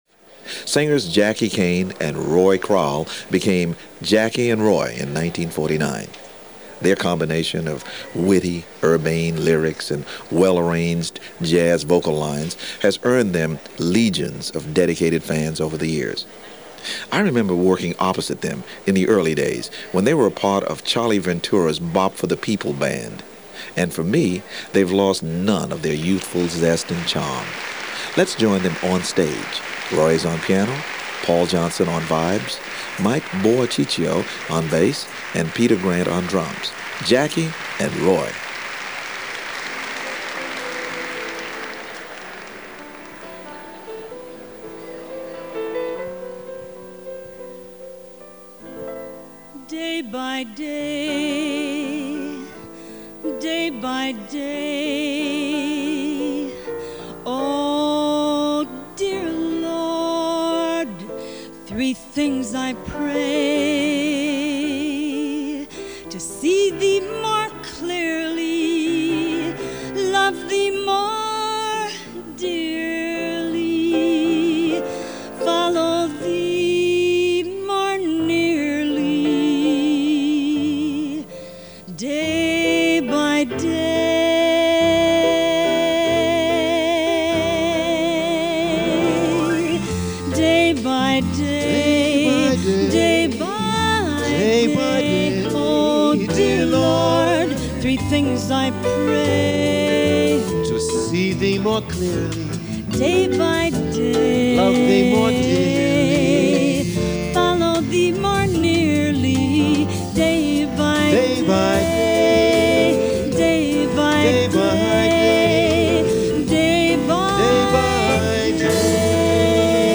The First Couple of Jazz Vocals.